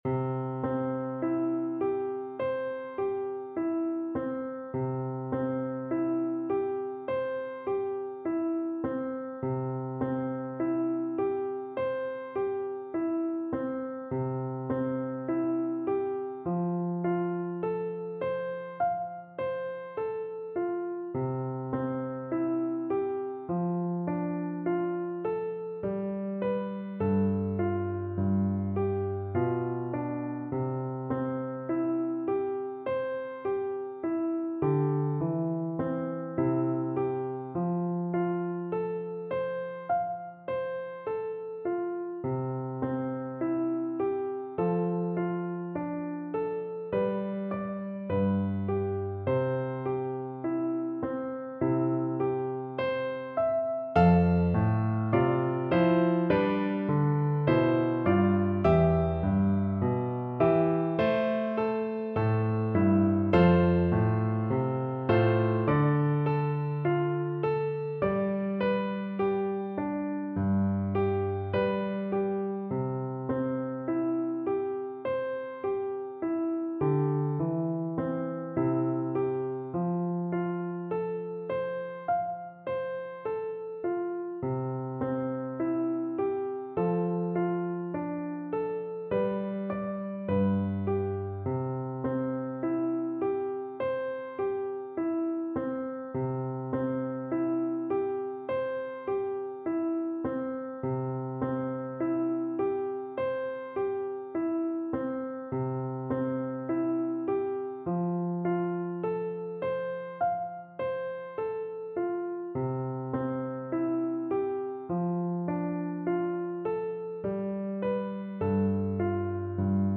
Andante
2/4 (View more 2/4 Music)
Traditional (View more Traditional Flute Music)